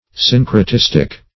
Syncretistic \Syn`cre*tis"tic\, a.
syncretistic.mp3